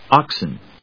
/άksn(米国英語), ˈɔksn(英国英語)/